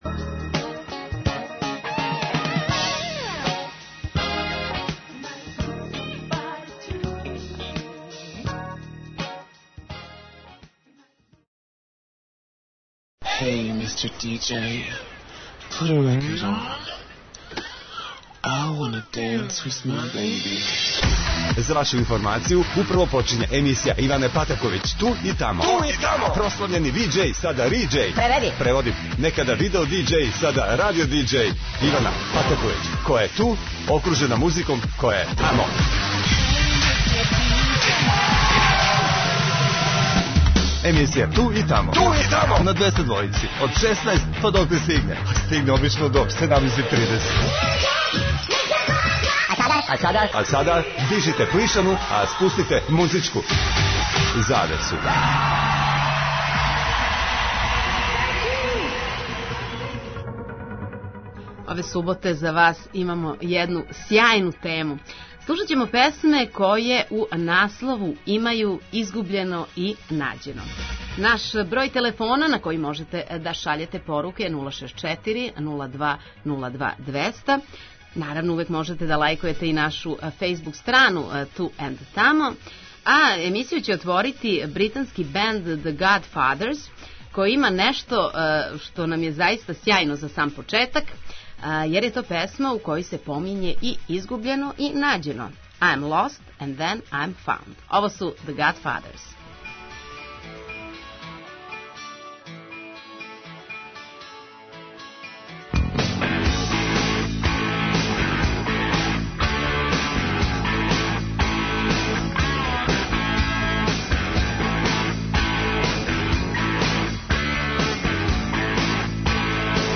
На таласима Двестадвојке завртеће се песме које у наслову имају - изгубљено и нађено!!!
преузми : 16.38 MB Ту и тамо Autor: Београд 202 Емисија Ту и тамо суботом од 16.00 доноси нове, занимљиве и распеване музичке теме. Очекују вас велики хитови, страни и домаћи, стари и нови, супер сарадње, песме из филмова, дуети и још много тога.